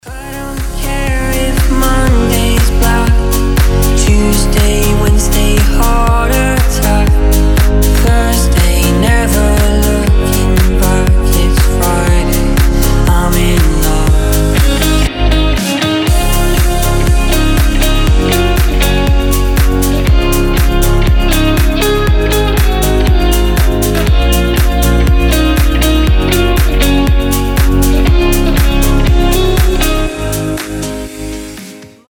красивые
теплые
Chill House